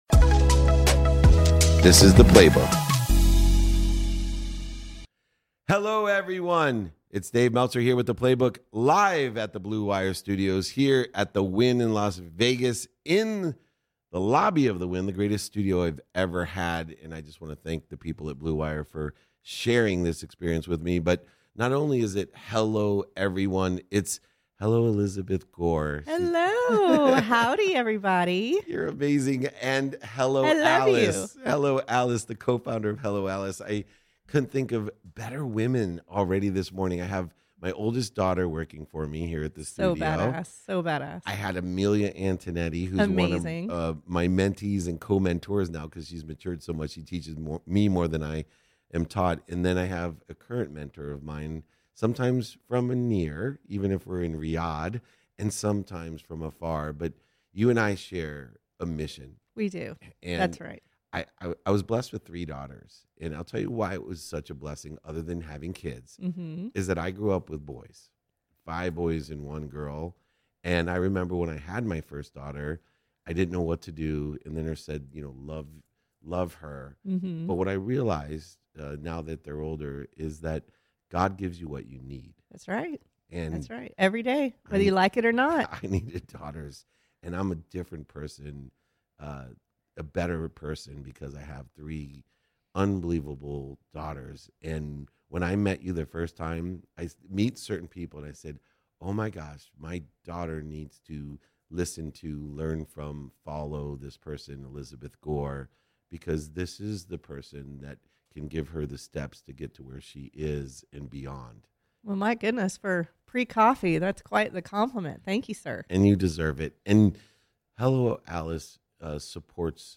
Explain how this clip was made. At CES 2023